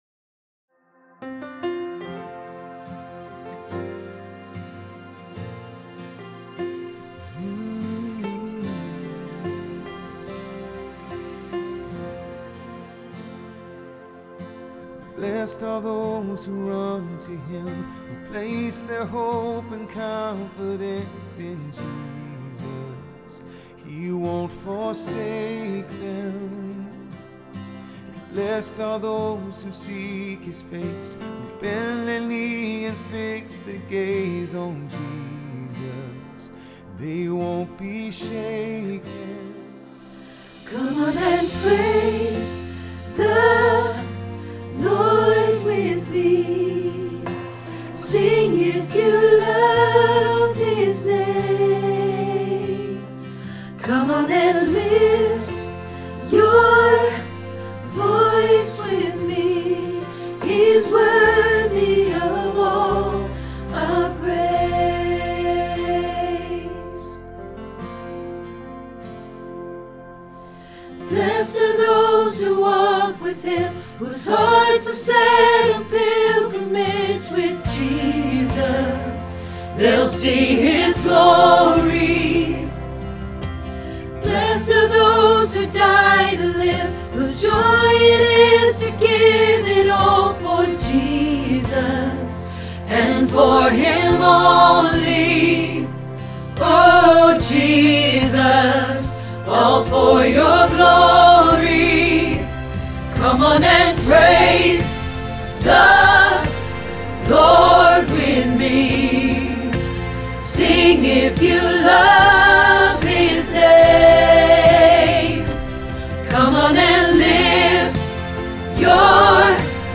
Bless God – Alto – Hilltop Choir
01-Bless-God-ALTO.mp3